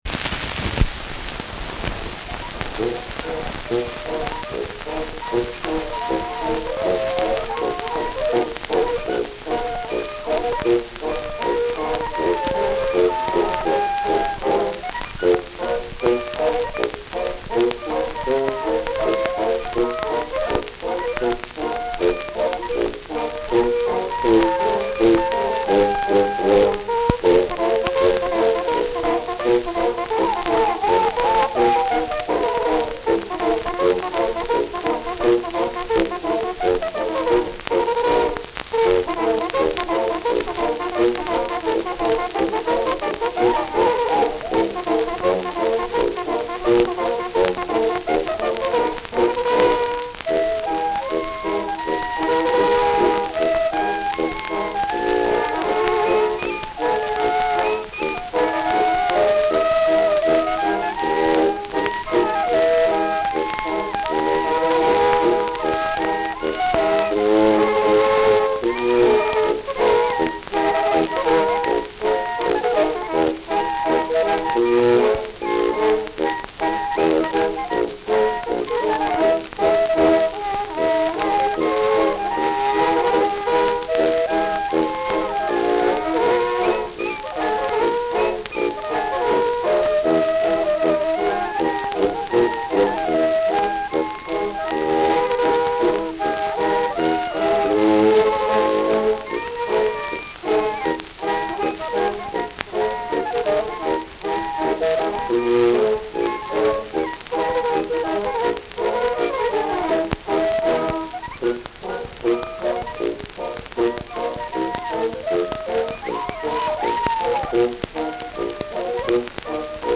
Schellackplatte
Stark abgespielt : Starkes Grundrauschen : Leichtes Leiern : Gelegentlich leichtes Knacken : Zum Schluss deutlich leiser und stärkeres Knacken
[Nürnberg] (Aufnahmeort)